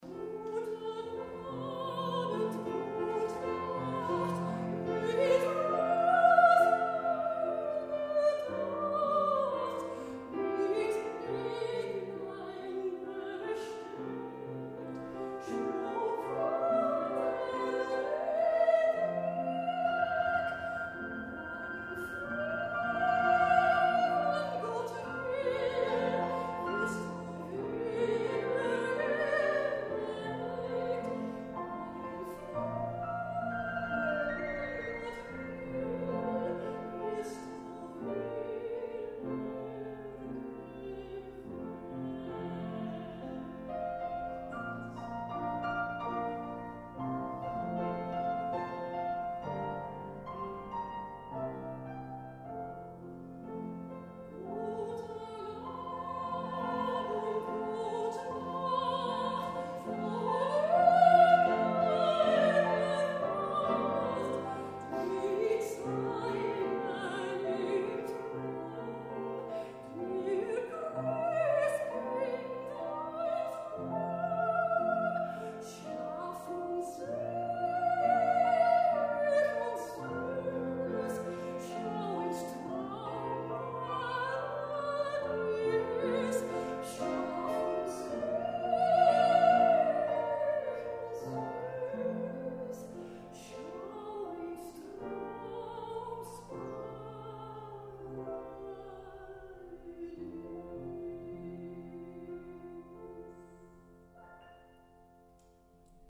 sopraan Muziekfragmenten